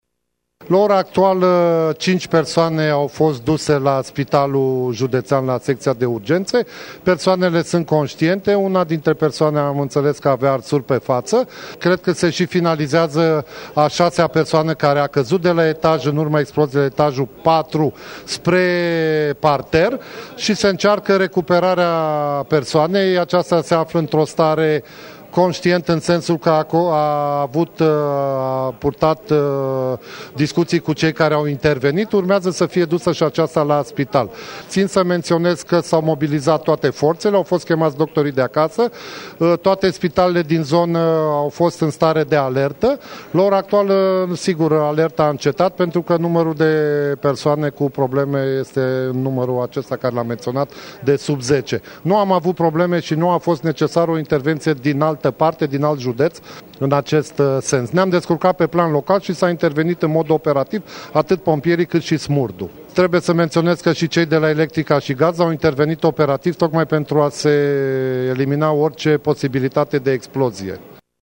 Prefectul Mihai Mohaci a declarat pentru Digi 24 că răniții, în stare conştientă, au fost transportați la Spitalul Clinic Judeţean de Urgenţă.